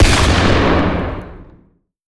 Sniper_Rifle5.ogg